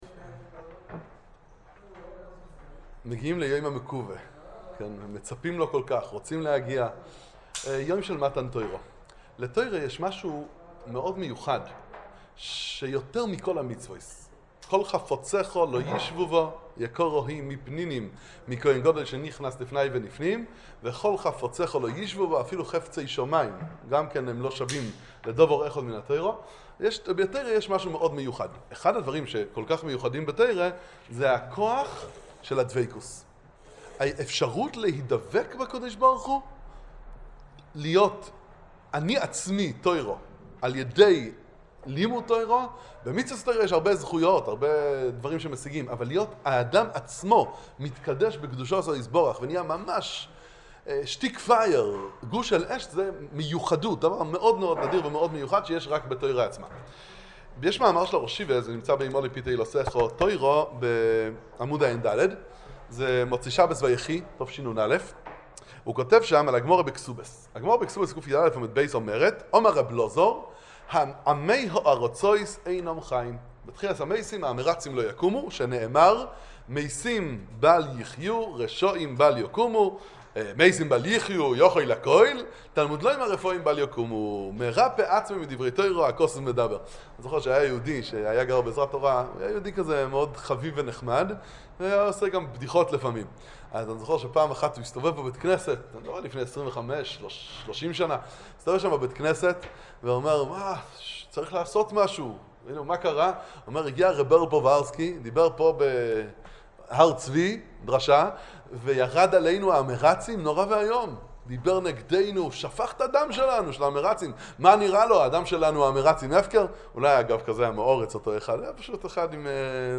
שיחת חיזוק